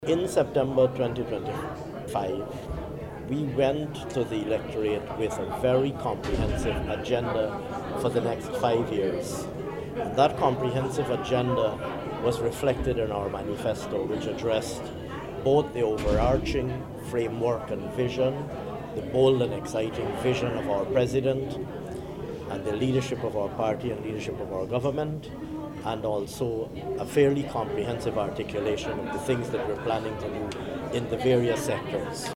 Finance Minister, Dr. Ashni Singh